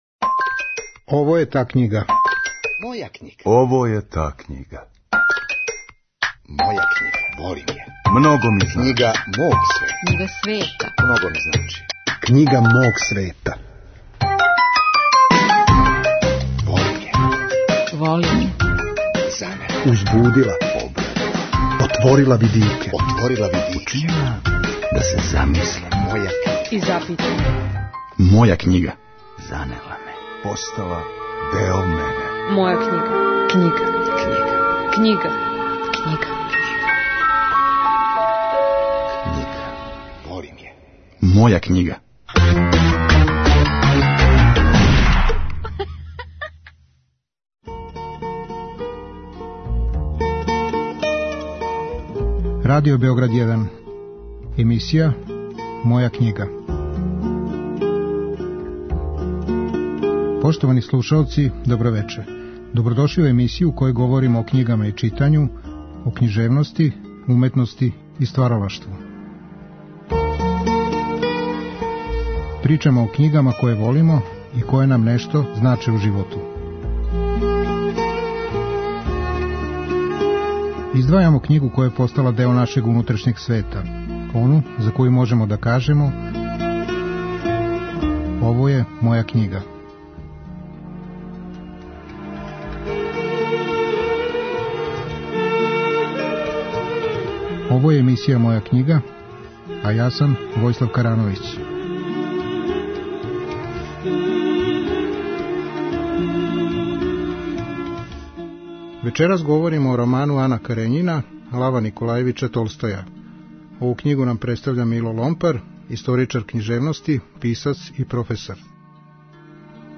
Наш саговорник говори о томе зашто овај роман сматра врхунским уметничким делом, о Толстојевој вештини приповедања, као и о лику Ане Карењине који, по свом трагичком и симболичком потенцијалу, може да се доведе у везу са ликовима као што су Софоклова Антигона, Флоберова Ема Бовари и Татјана из Пушковог дела ''Евгеније Оњегин''.